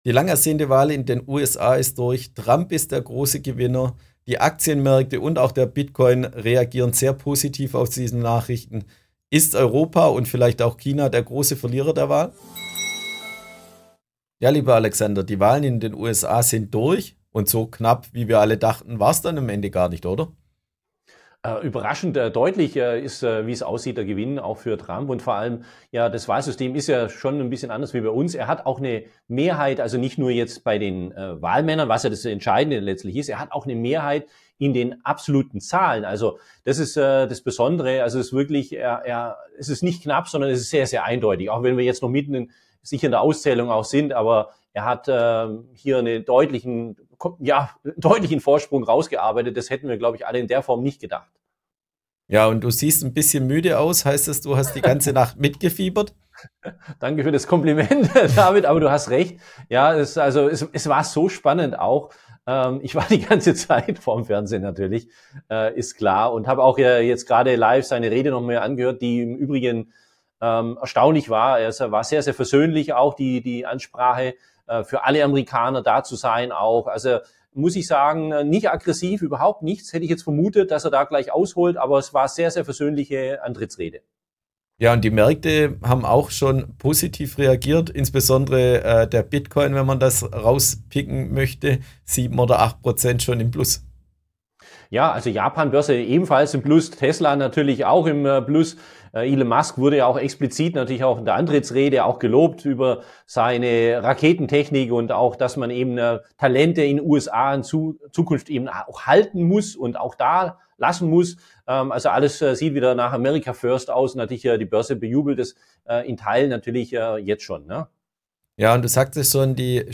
Experteninterview